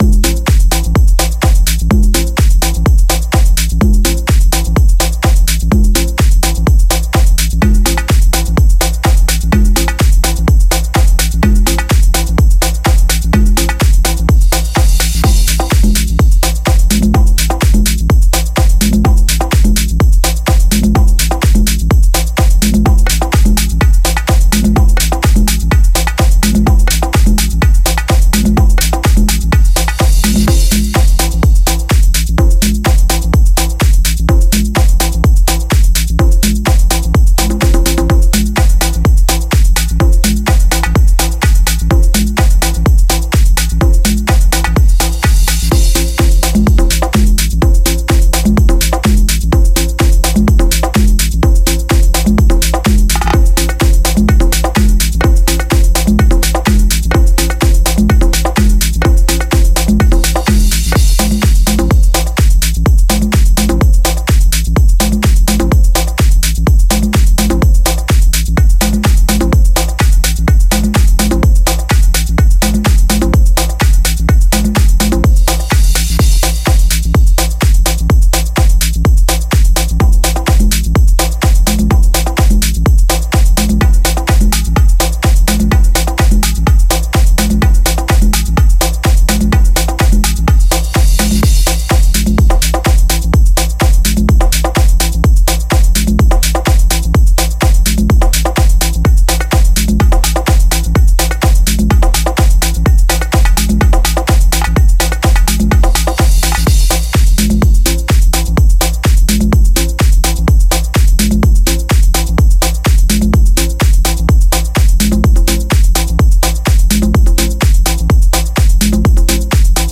本コレクションの中心には、タイトで表現力豊かなパターンを提供する120のボンゴループがあります。
さらに、より深く共鳴するリズムテクスチャをもたらす120のコンガループも収録されています。
デモサウンドはコチラ↓
Genre:Tech House
BPM 126
120 Bongo Loops
120 Conga Loops